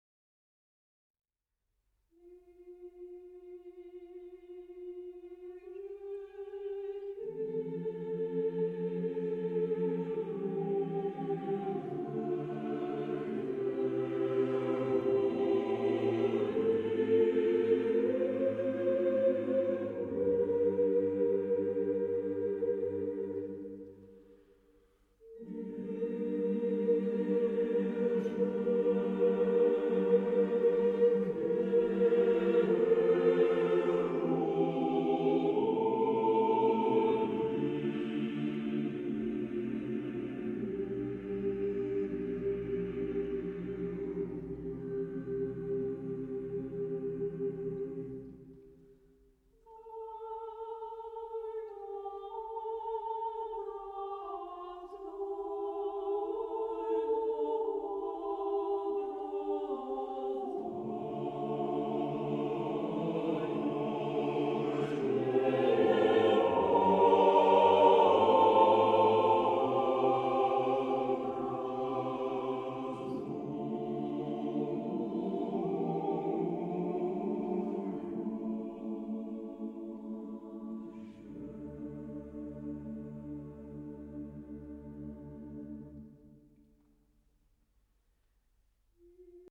Himno litúrgico (ortodoxo)
Carácter de la pieza : solemne ; piadoso
SATB (4 voces Coro mixto )
Tonalidad : fa mayor